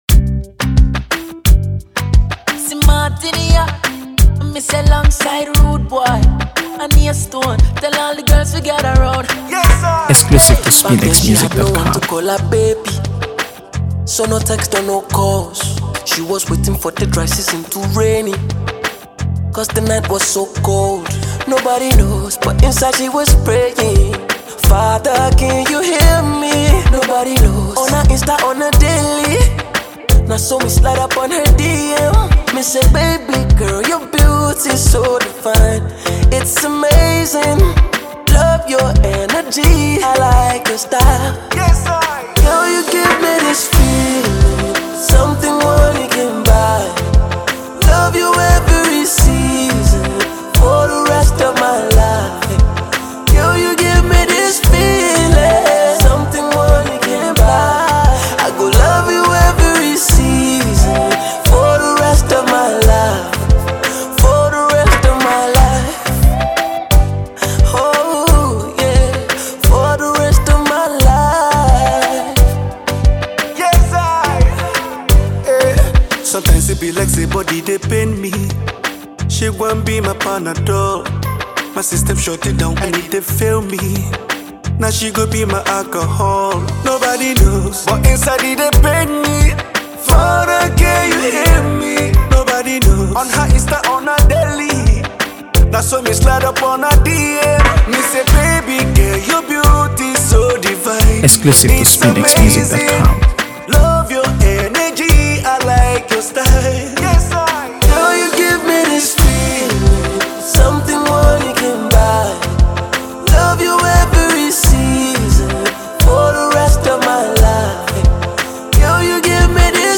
AfroBeats | AfroBeats songs
Nigerian singer, songwriter, and performer
soulful and sensual music